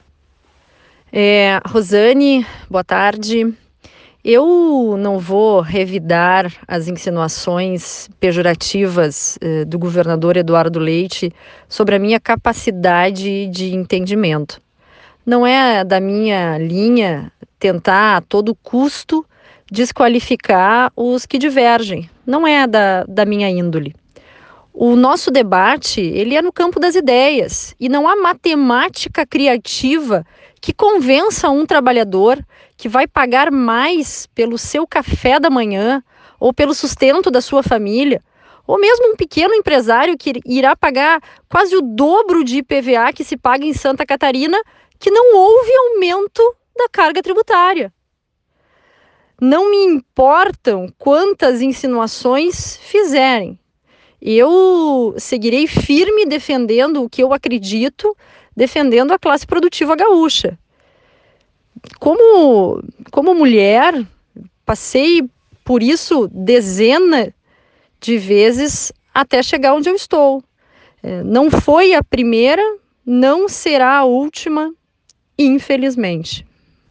desta vez em uma entrevista na Rádio Gaúcha.